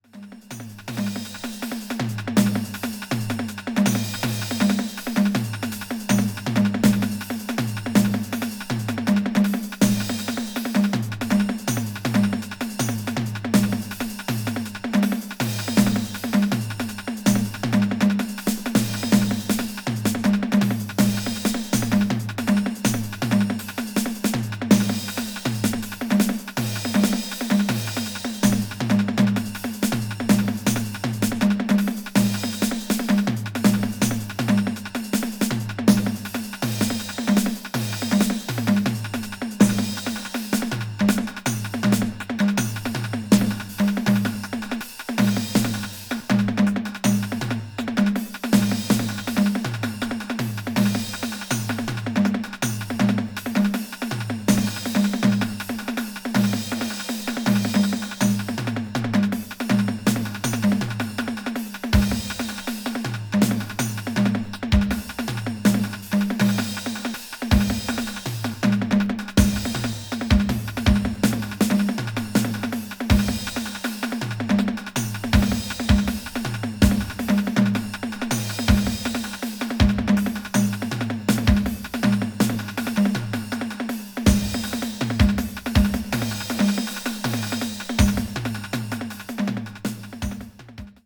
media : EX/EX(わずかにチリノイズが入る箇所あり)